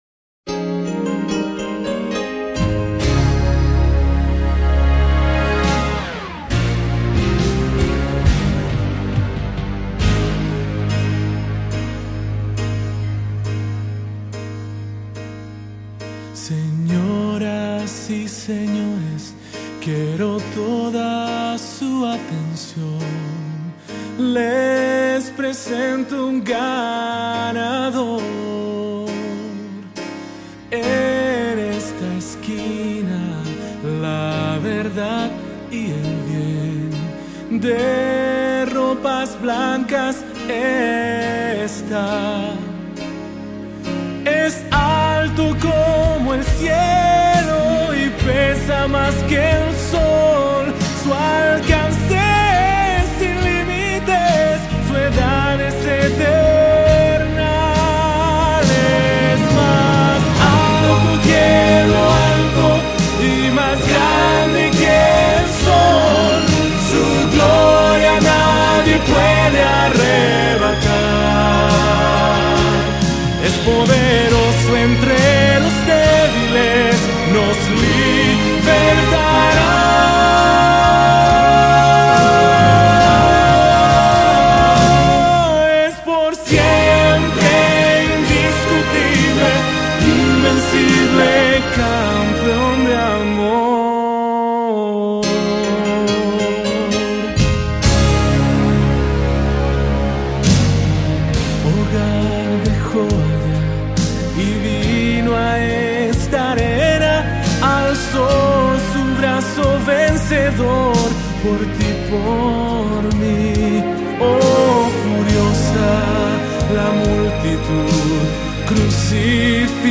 Música cristiana